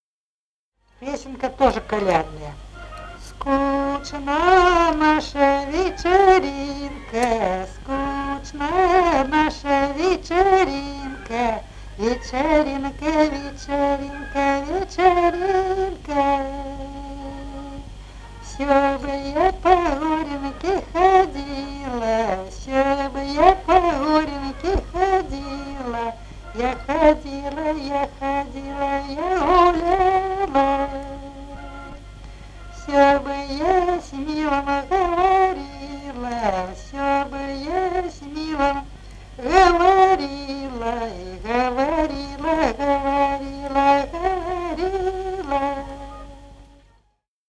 Народные песни Смоленской области
«Скучна наша вечеринка» («колядная» вечерочная).